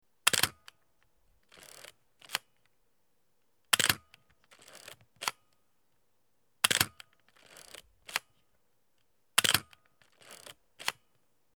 ↑ PENTAX67 のシャッター音
撮られてる被写体をビビらす迫力の重低音！
PENTAX67-ShutterSound.mp3